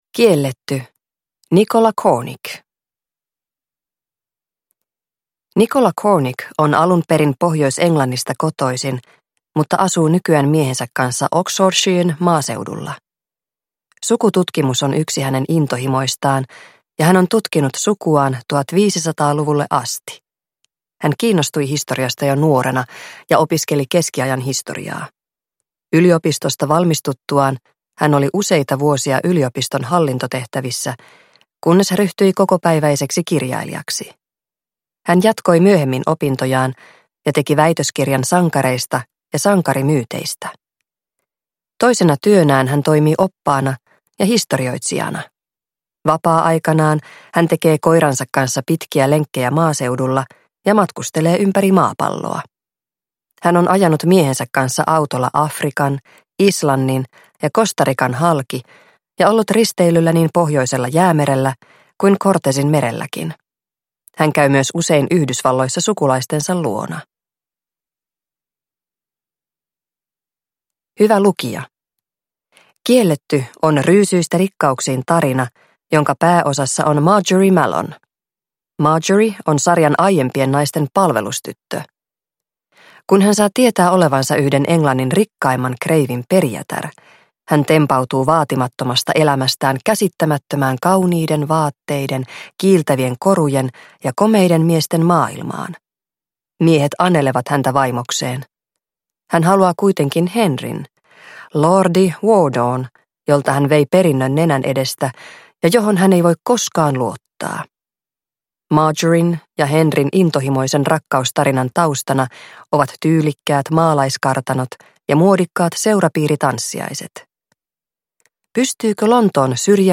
Kielletty – Ljudbok